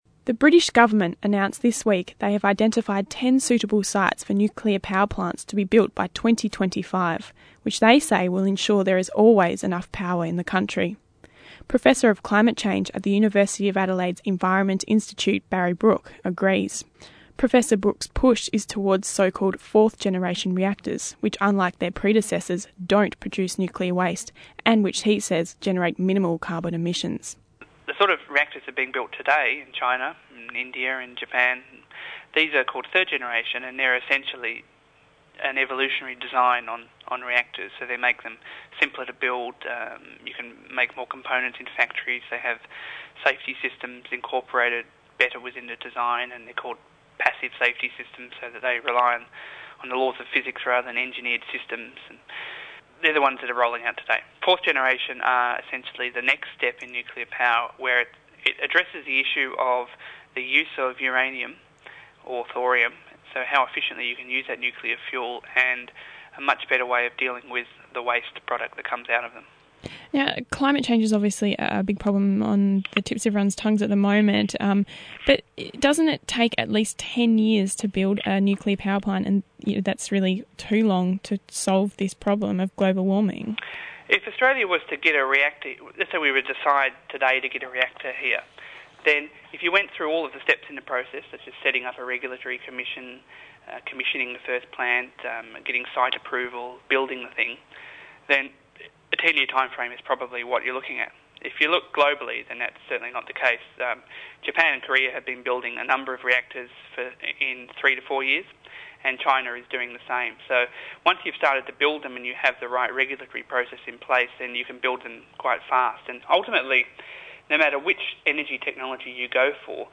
Here is an interview I did yesterday on this topic on ABC’s The Wire radio programme.